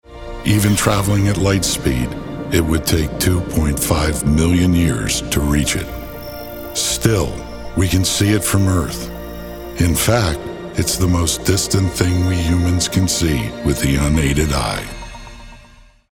Documentaries
Whether it is a directed session, or done remotely in my home studio, we will execute the script on the background gained from the Planning stage..
Space-Documentary.mp3